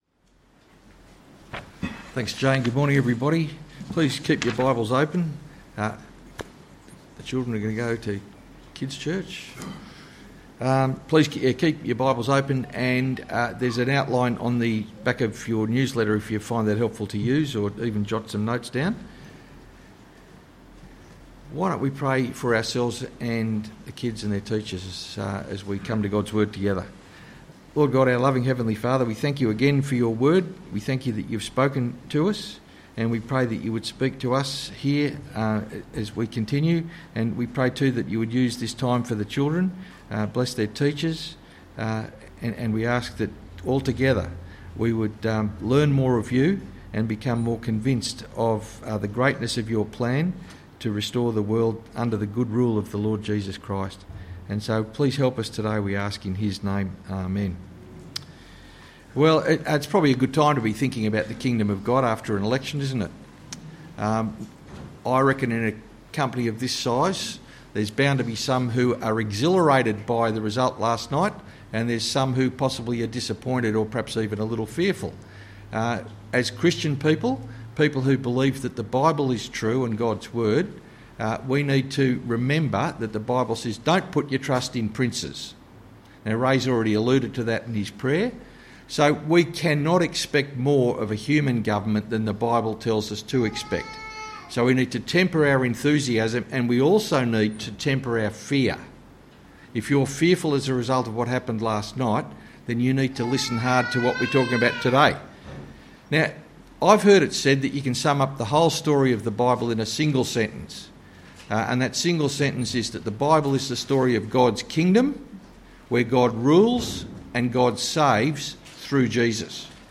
Sermon: Matthew 15:1-20